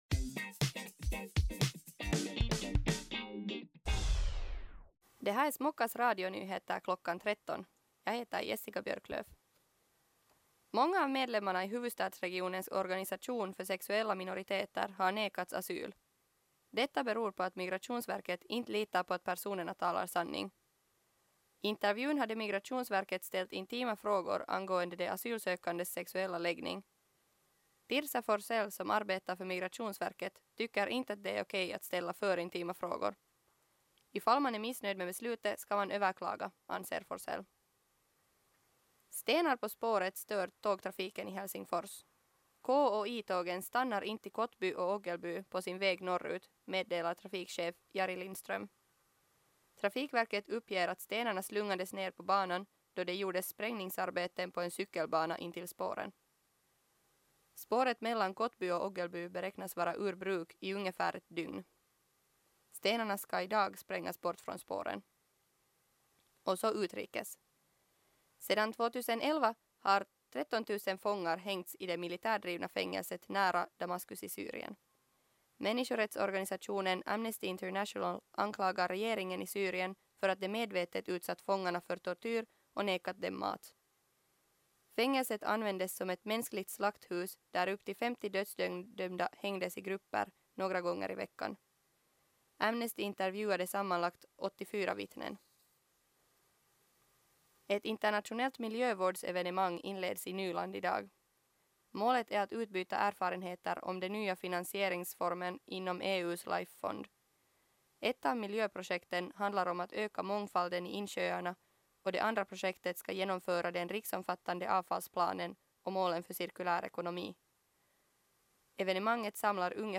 Radionyheter kl. 13